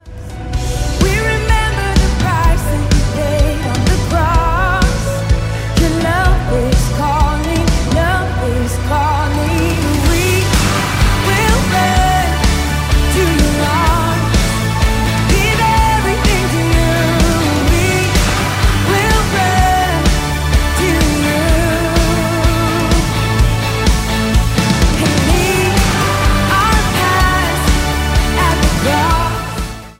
Szenvedélyes, megállíthatatlan dicsőítés